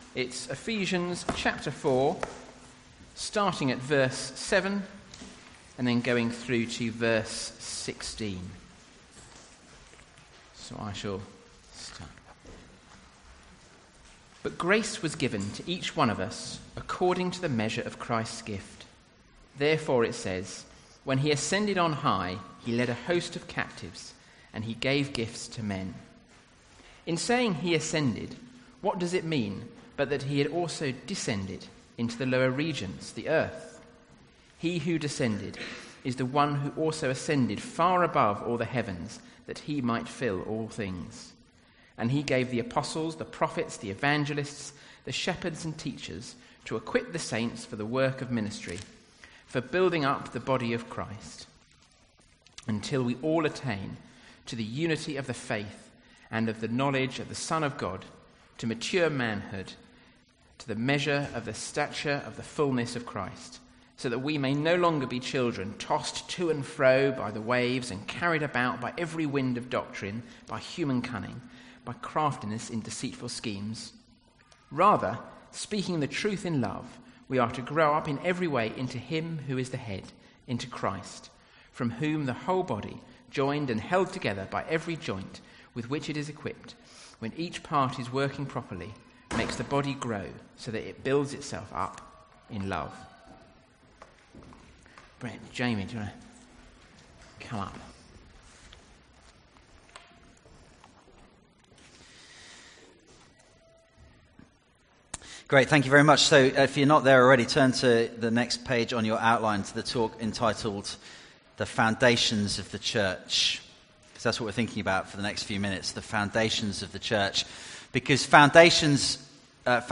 Series: Weekend Away Oct 2019 | Church